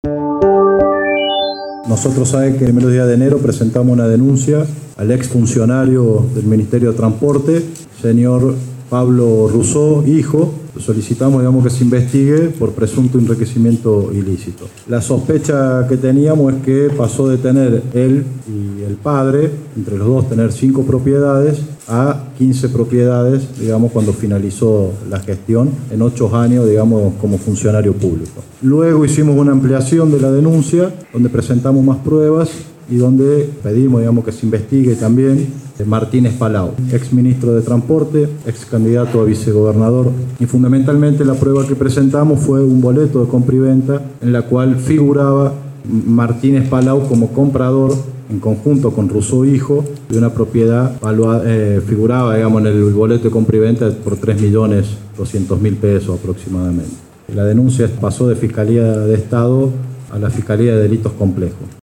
Audio senador Ernesto Mancinelli